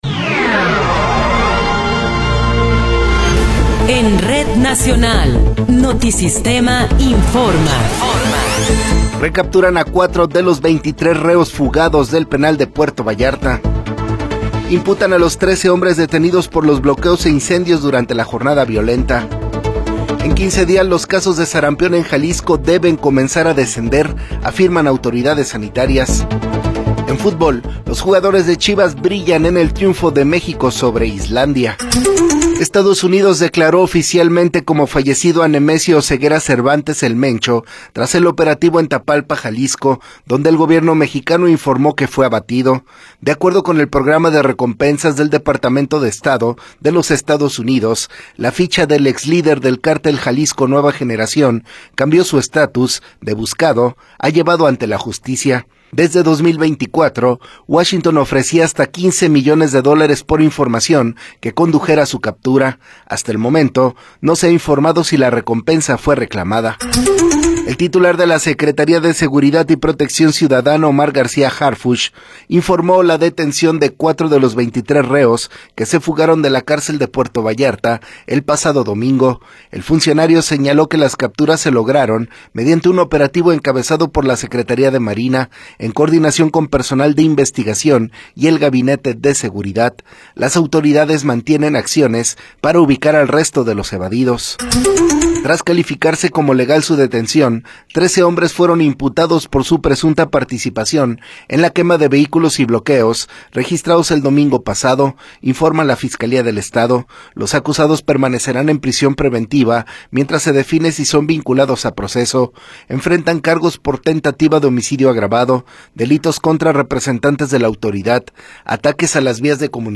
Noticiero 9 hrs. – 26 de Febrero de 2026
Resumen informativo Notisistema, la mejor y más completa información cada hora en la hora.